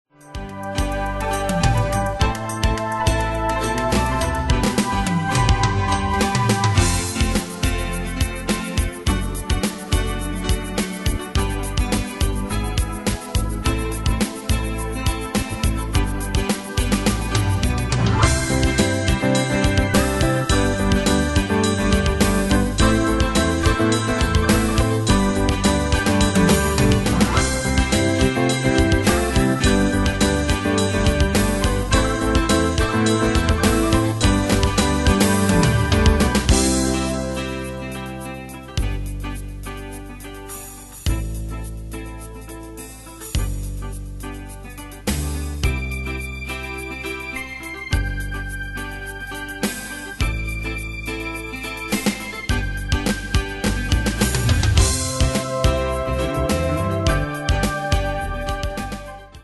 Style: PopFranco Ane/Year: 1998 Tempo: 105 Durée/Time: 4.19
Danse/Dance: PopRock Cat Id.
Pro Backing Tracks